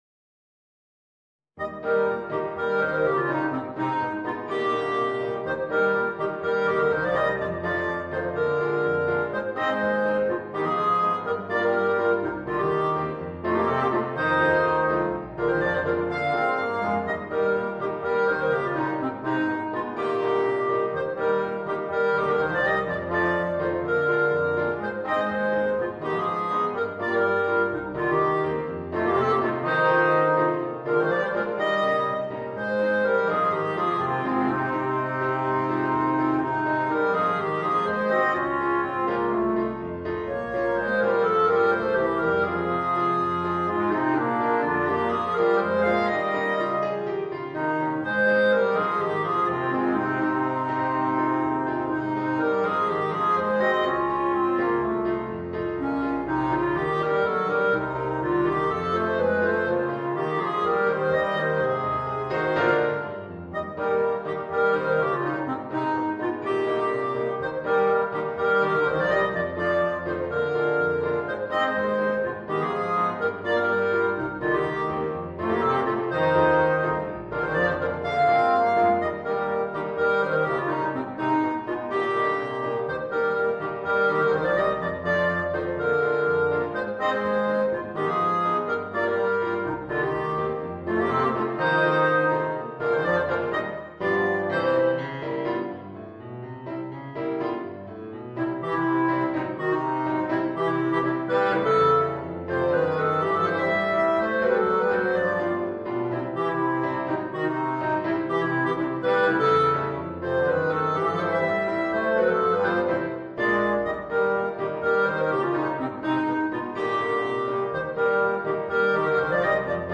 Voicing: Clarinet, Trombone and Piano